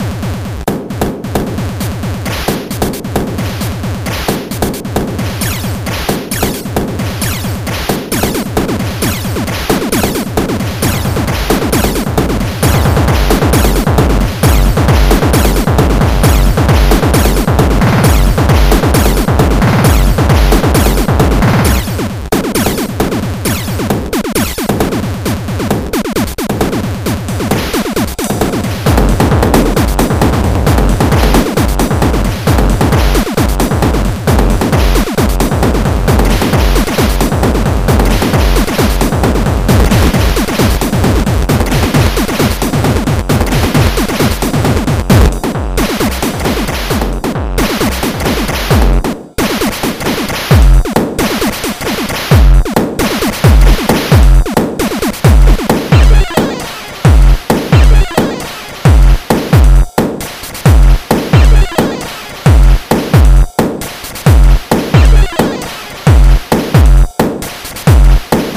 Frantic music.